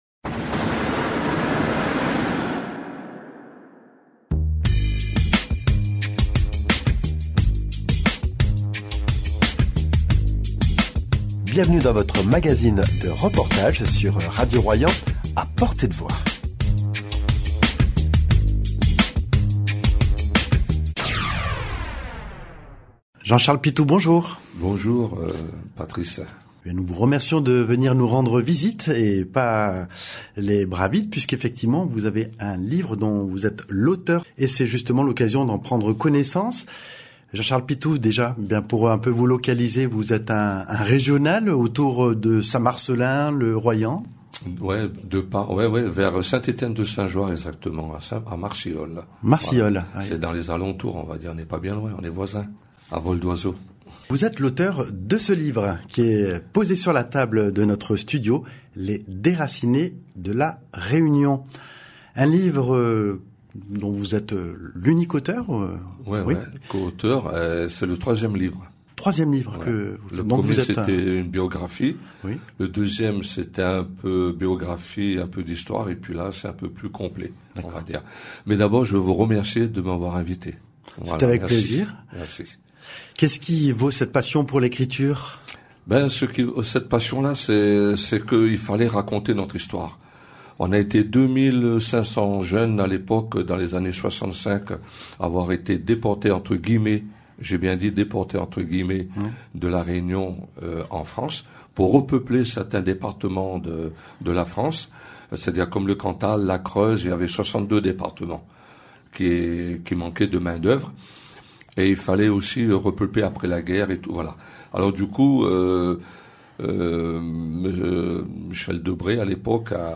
Entretien dans notre studio